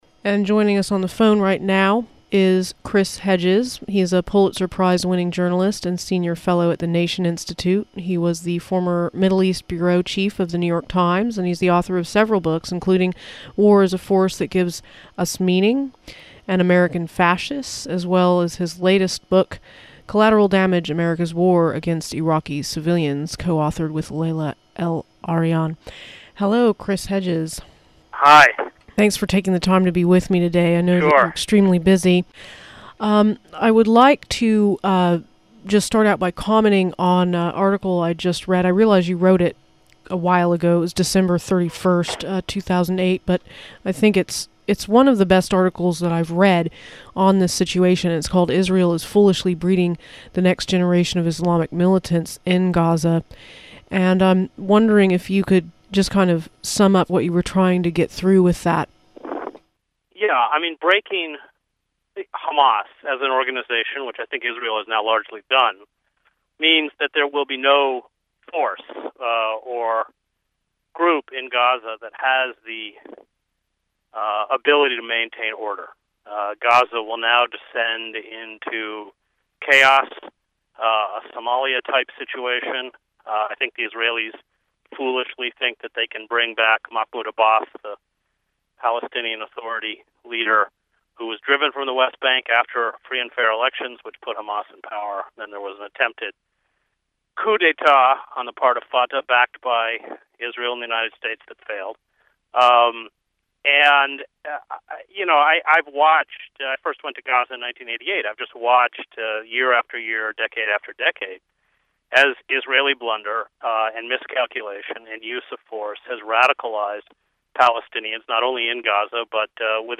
I interviewed veteran war correspondent, author and lecturer Chris Hedges on 1-15-09 about his latest article, "Language Of Death," and what is likely to come after Israel's assault on Gaza officially comes to an end.